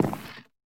creaking_heart_step4.ogg